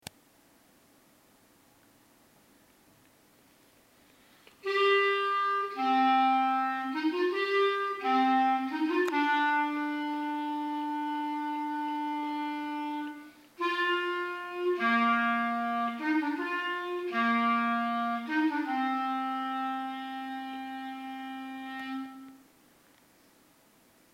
- Compás: 6/8.
- Tonalidad: Do menor
Clarinete en Si bemol